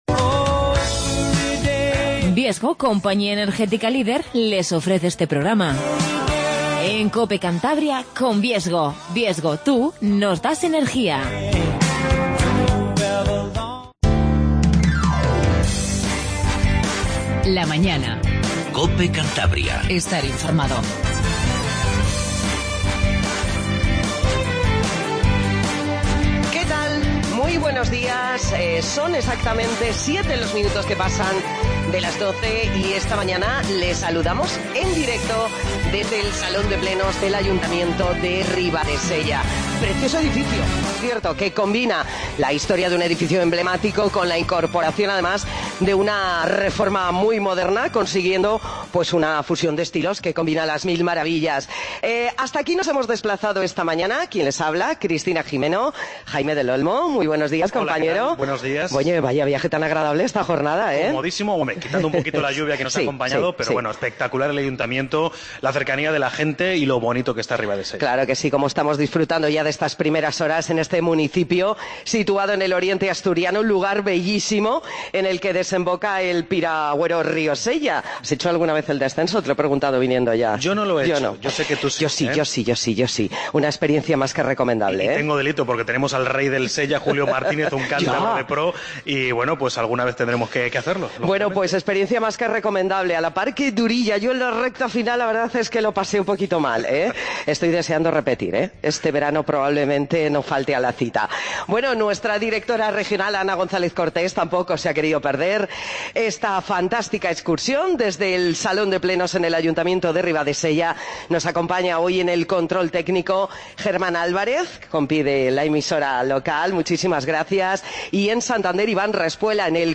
AUDIO: Hoy la mañana en Cantabria desde el Ayuntamiento de Ribadesella. Hablamos con su alcaldesa, Rosario Fernández Román.